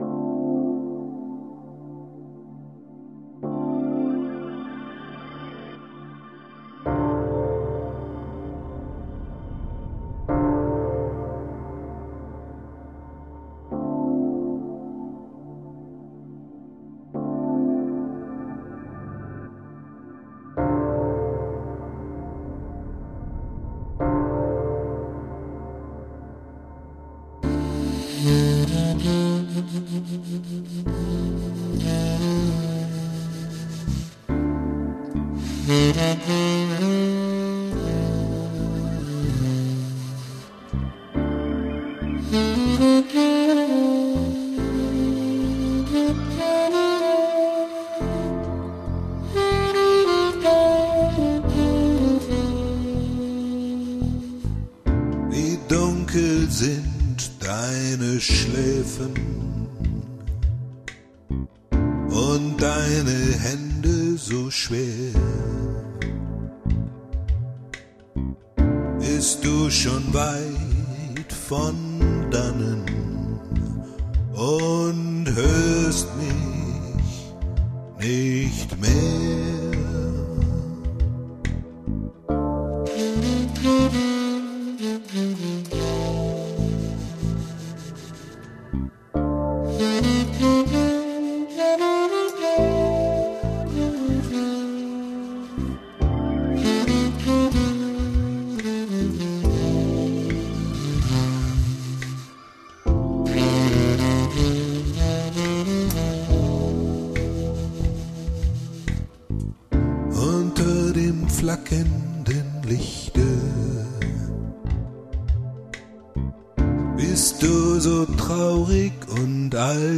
Vertonung eines Gedichtes